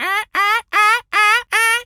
pgs/Assets/Audio/Animal_Impersonations/seagul_squawk_seq_02.wav at master
seagul_squawk_seq_02.wav